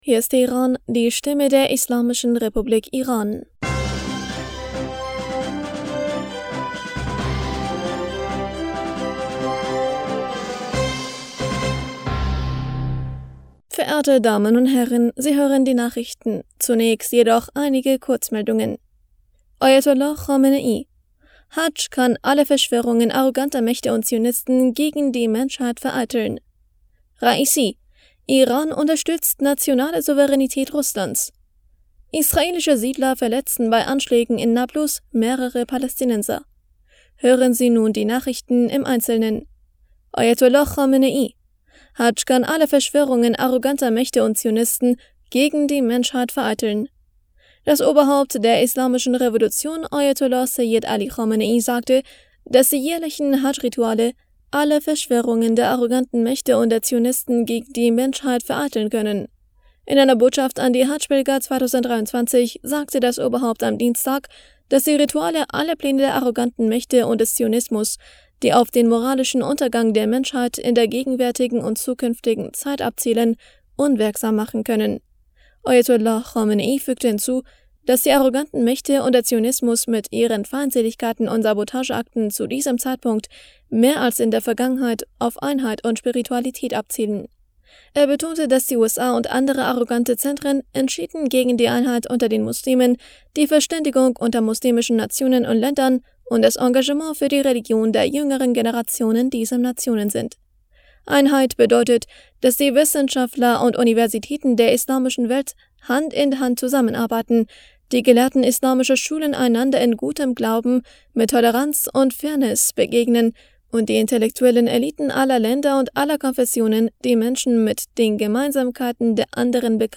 Die Nachrichten von Dienstag, dem 27. Juni 2023